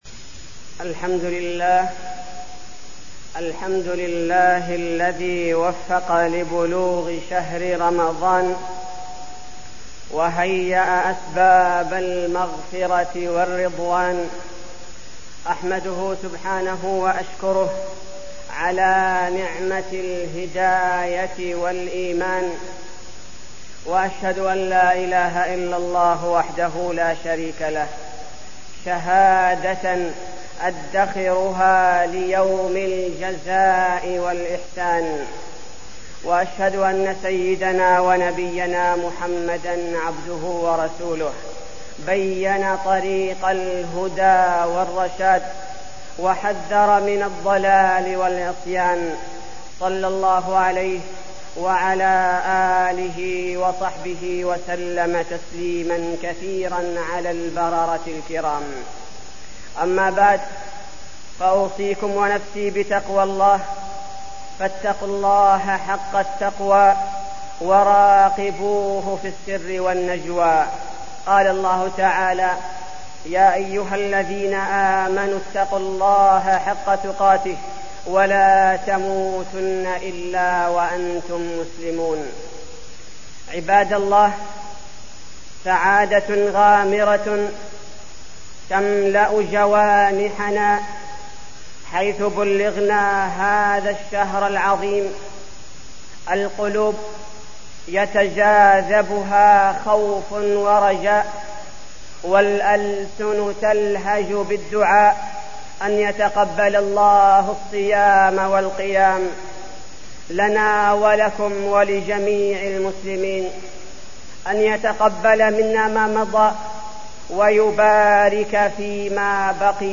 تاريخ النشر ٢٥ رمضان ١٤١٨ هـ المكان: المسجد النبوي الشيخ: فضيلة الشيخ عبدالباري الثبيتي فضيلة الشيخ عبدالباري الثبيتي وداع رمضان